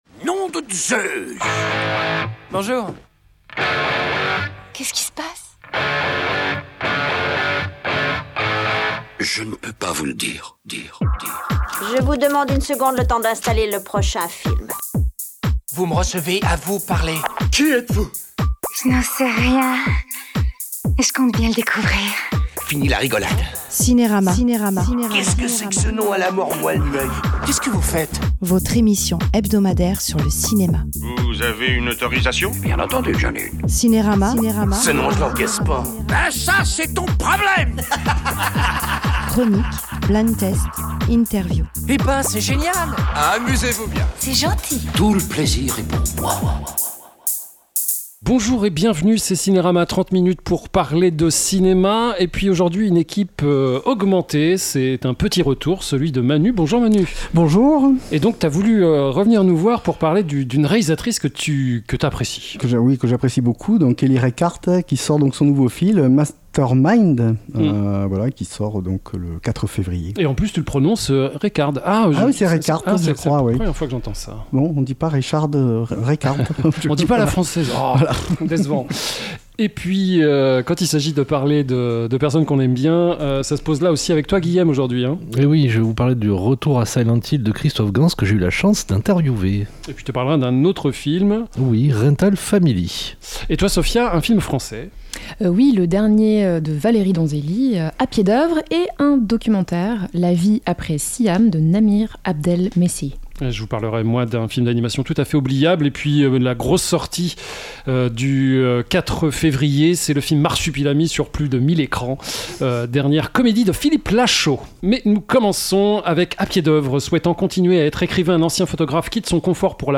Au sommaire :L’INTERVIEWNous avons pu rencontrer le très bavard Christophe Gans qui sort Retour à Silent Hill, 20 ans après le premier opus tiré du jeu vidéo.